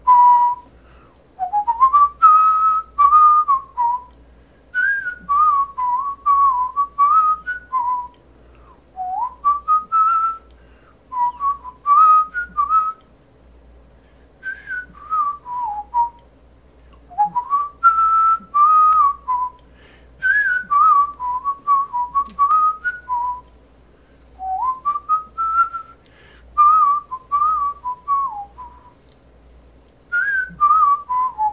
Of me whistling.